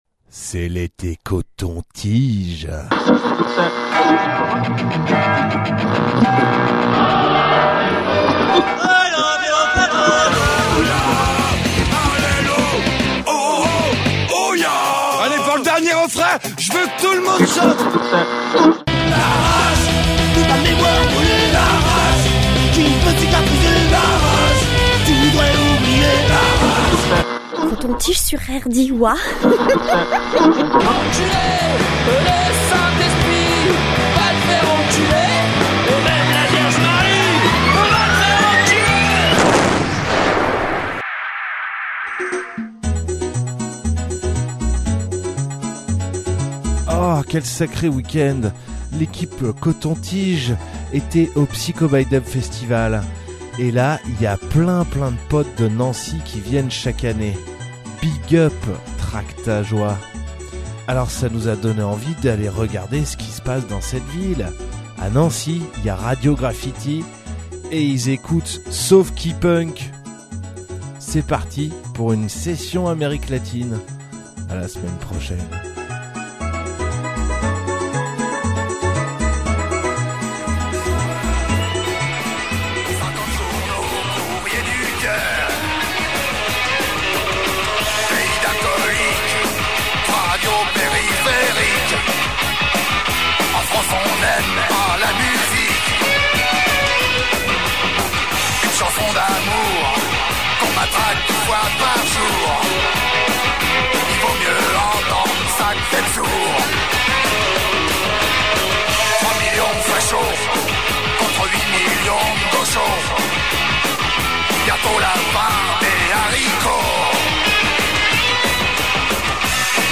punk